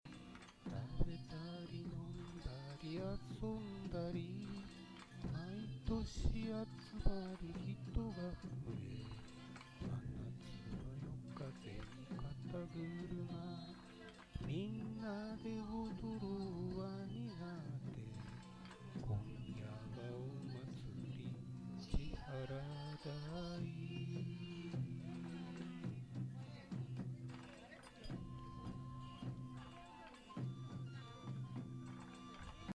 win8.1カラオケ
マイクからの声の音質は悪いです。
マイクからの声が割れやすくなります。
テスト録音したカラオケ状態の音